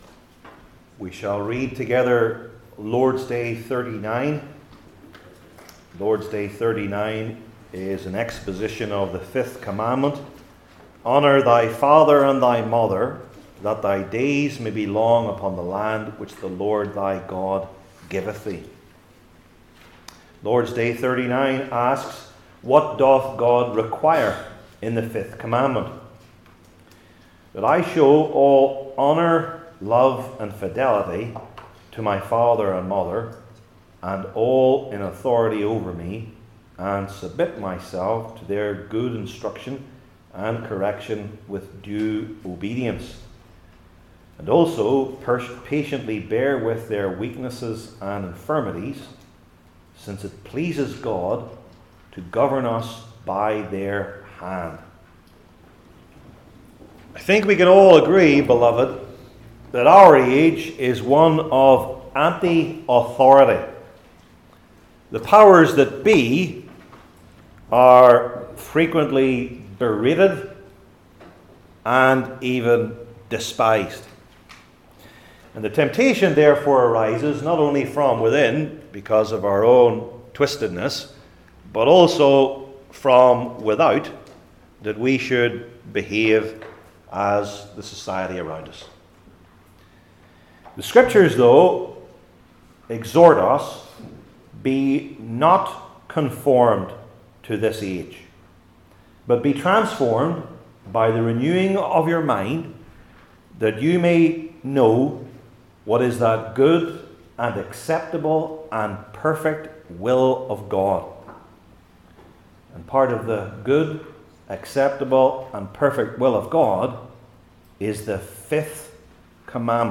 Heidelberg Catechism Sermons I. The Idea of Authority II.